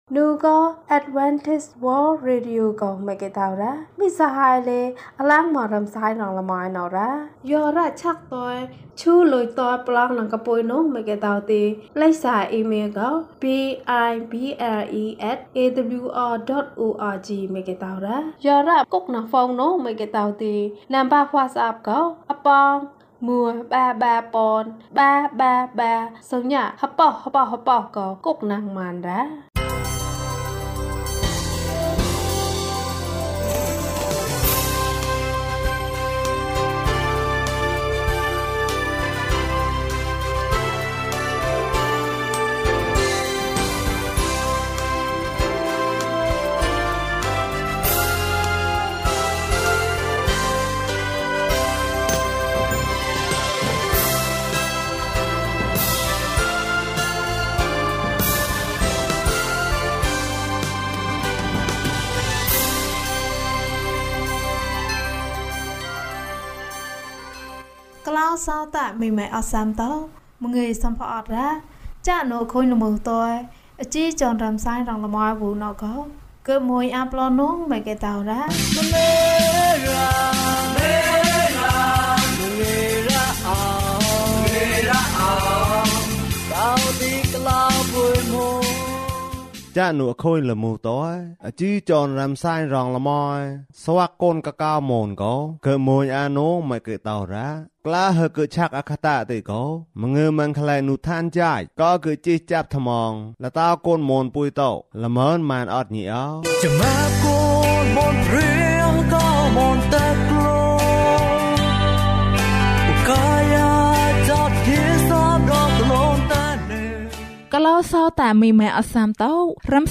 ငါ့အသက်ကို ပို့ဆောင်ပါ။၀၁ ကျန်းမာခြင်းအကြောင်းအရာ။ ဓမ္မသီချင်း။ တရားဒေသနာ။